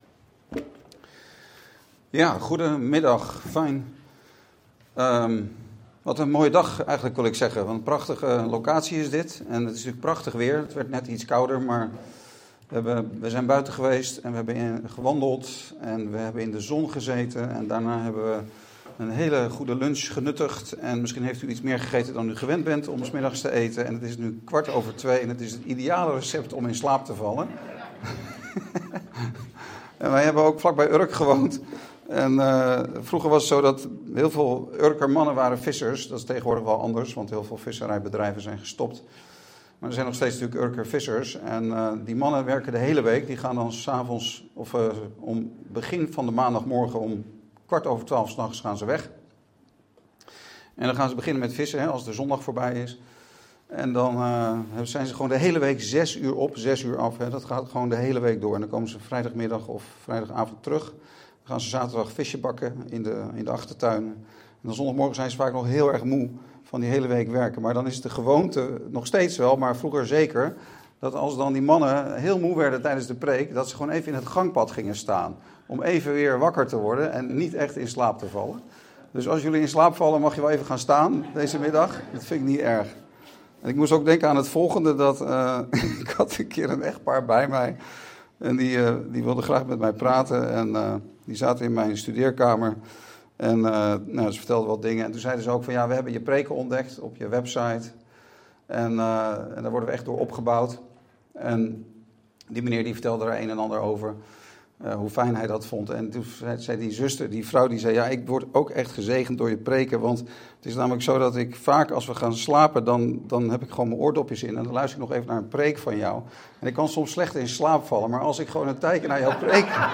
Dienstsoort: Studiedag
Huwelijksdag-lezing-2.mp3